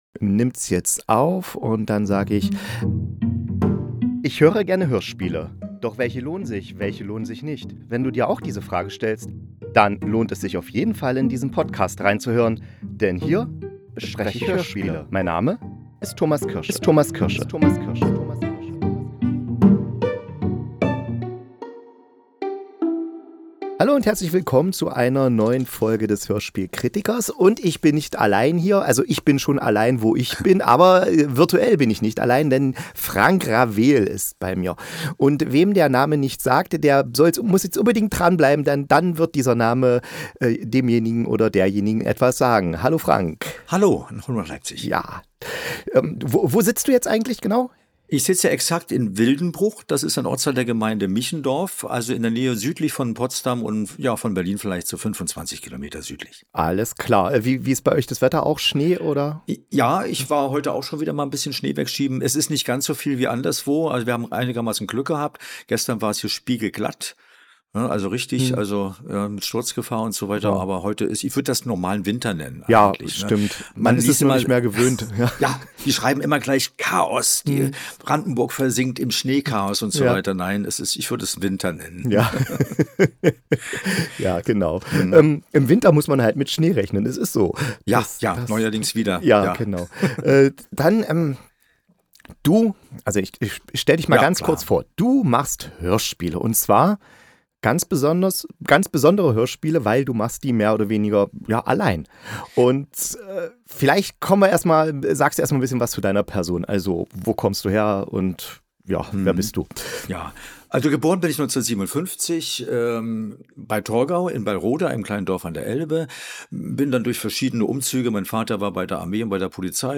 Leises Lärmen: Interview